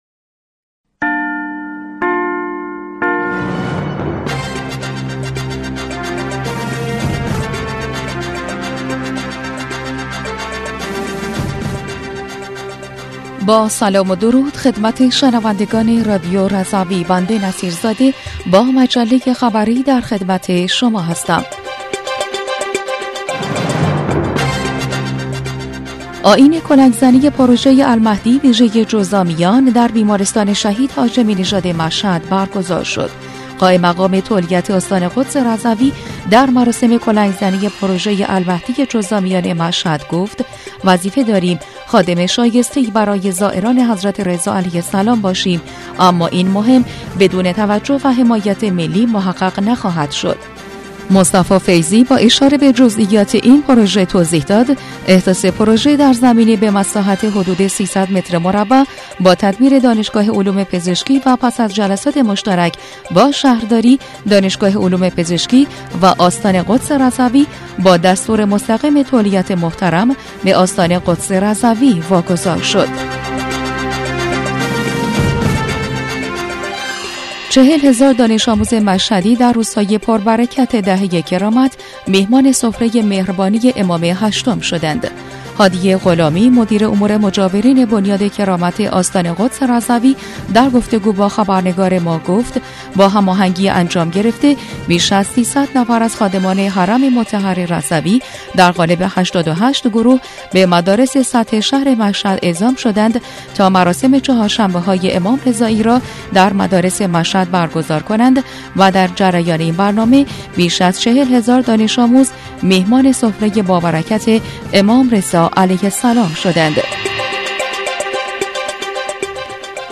بسته خبری 13اردیبهشت رادیو رضوی؛